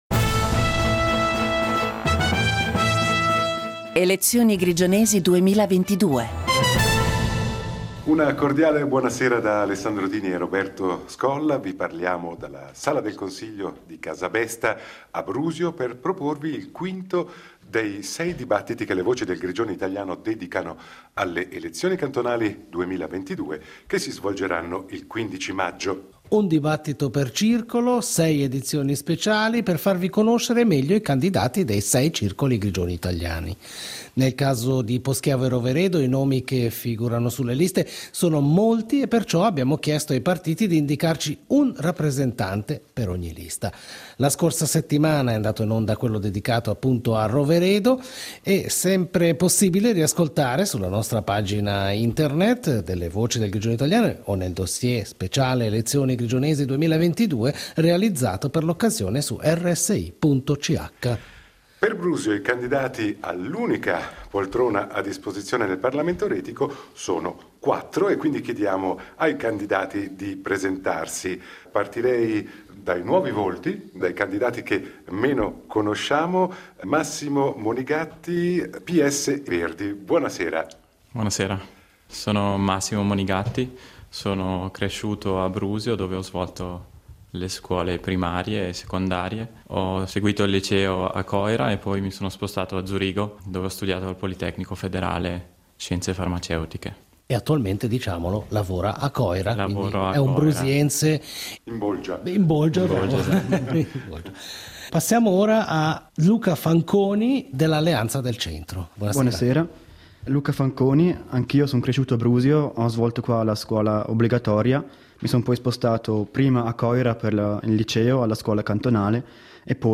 Dibattito in vista delle elezioni retiche del 15 maggio 2022